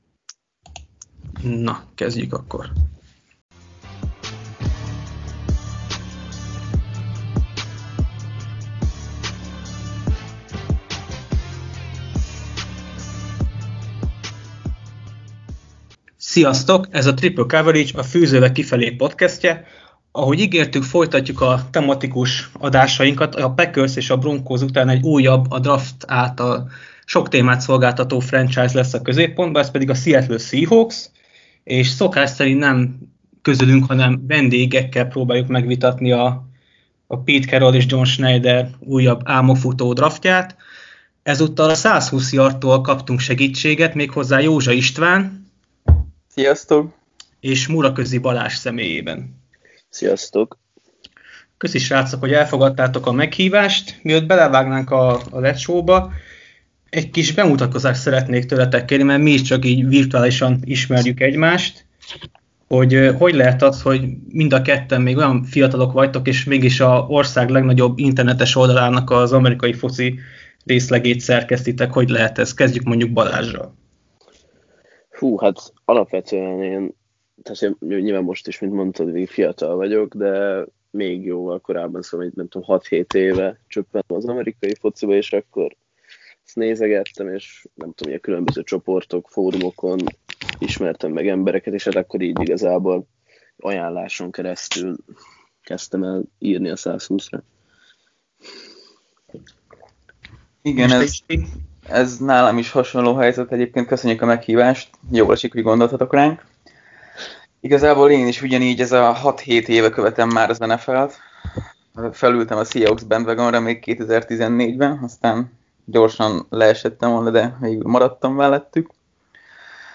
Folytatjuk a tematikus adások sorozatát szurkolókkal kibeszélve, ahol a megosztó draftjairól, ám ennek ellenére rendre playoff részvételeiről híres Seattle Seahawks nyújtott csámcsogni valót.